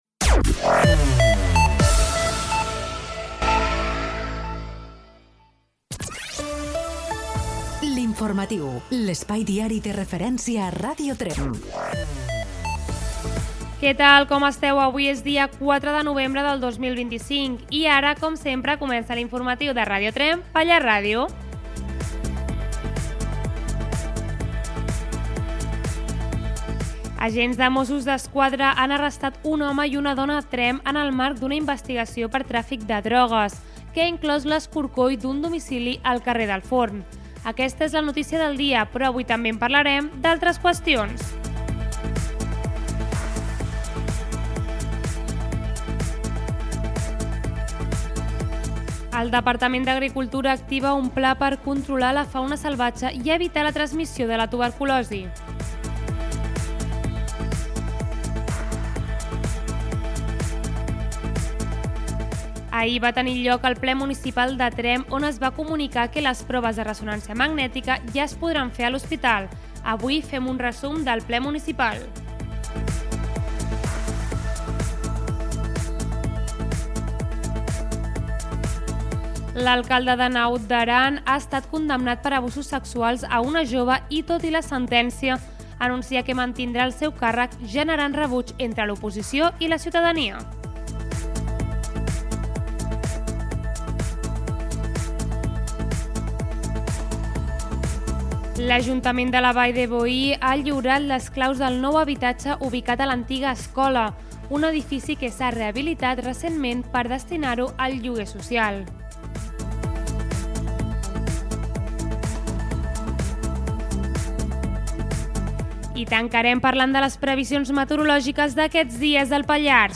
Careta del programa, resum de titulars
Informatiu